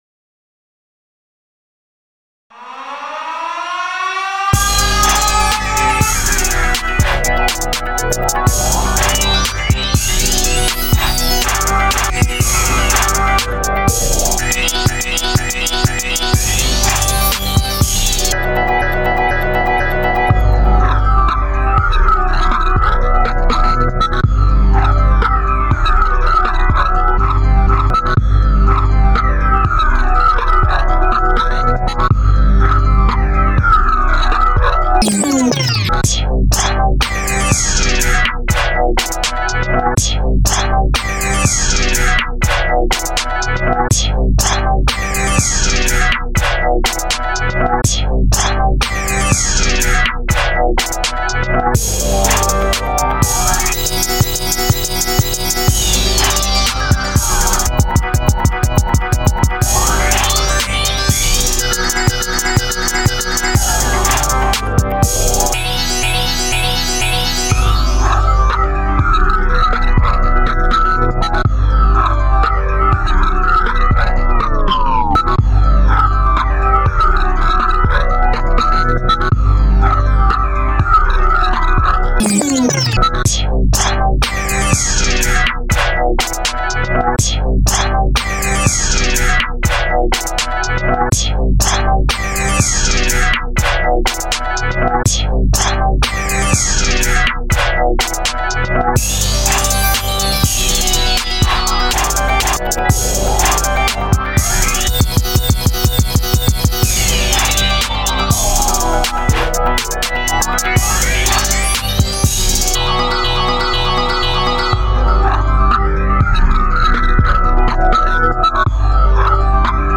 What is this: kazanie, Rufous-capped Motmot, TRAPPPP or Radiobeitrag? TRAPPPP